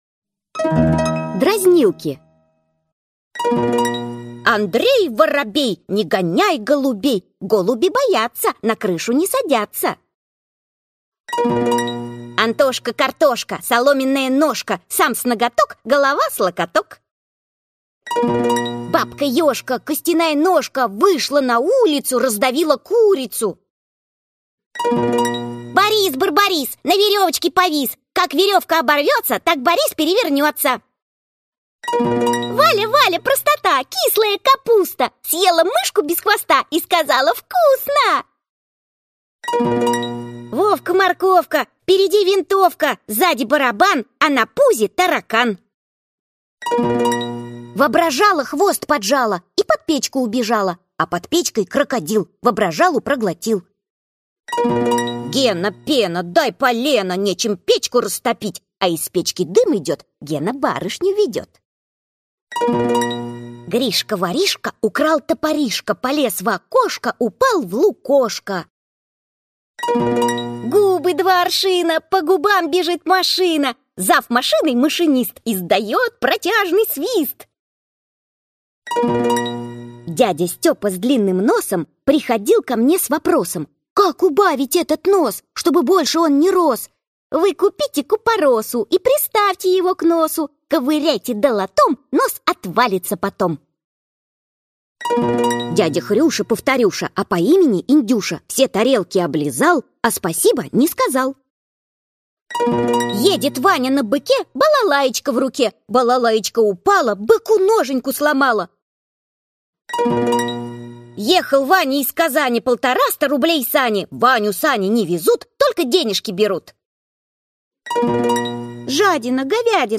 Аудиокнига Хрестоматия 1 класс. Русский фольклор | Библиотека аудиокниг